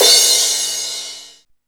CRASH09   -R.wav